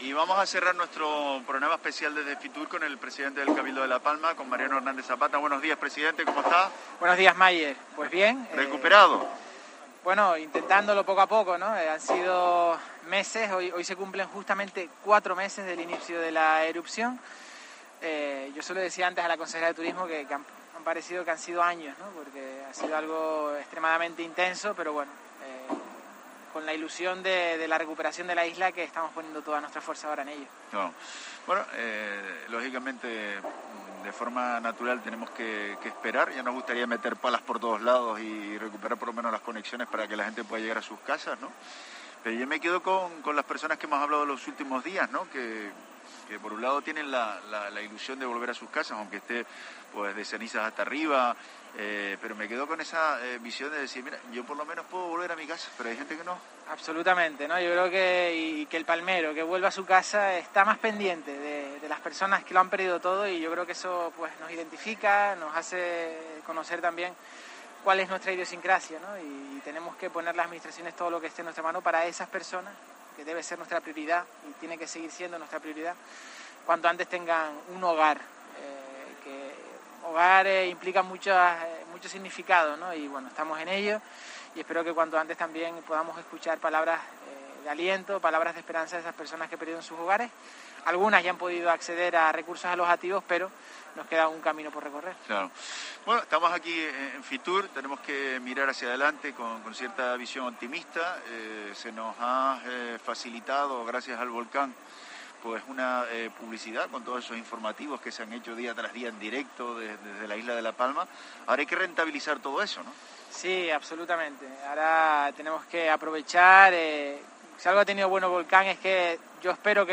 Mariano Hernández Zapata, presidente del Cabildo de La Palma, en La Mañana en Canarias desde Fitur